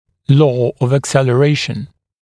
[lɔː əv əkˌselə’reɪʃn][ло: ов экˌсэлэ’рэйшн]закон ускорения